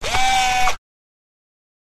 Descarga de Sonidos mp3 Gratis: robot 14.
robot.mp3